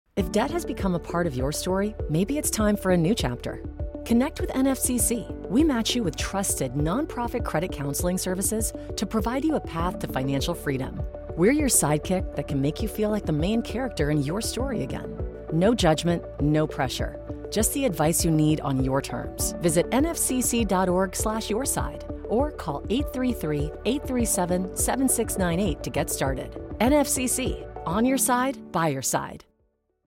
2026 Radio PSA